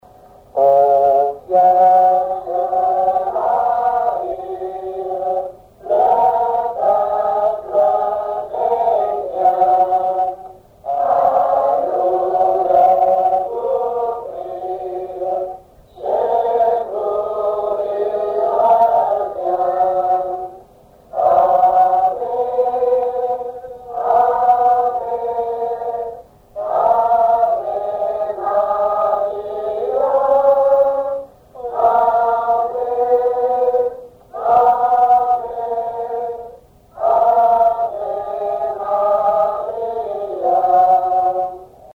enfantine : prière, cantique
Genre strophique
Pièce musicale inédite